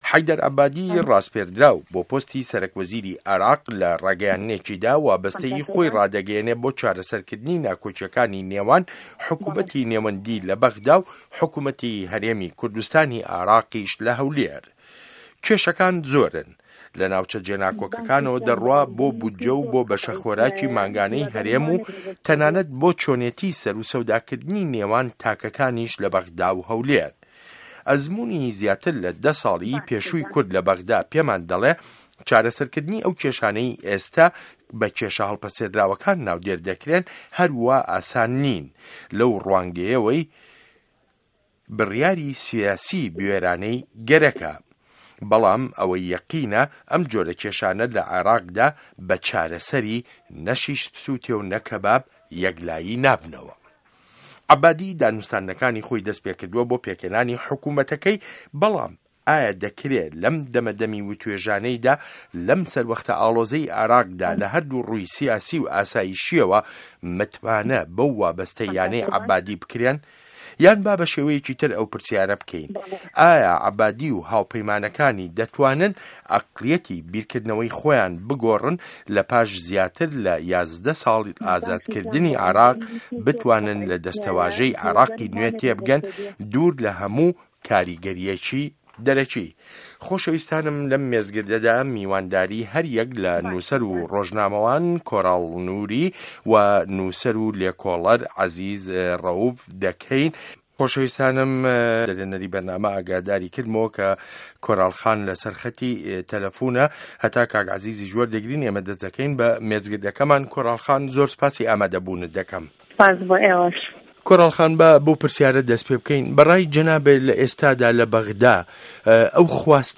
مێزگرد: عه‌بادی و کێشه‌کانی به‌غدا و هه‌ولێر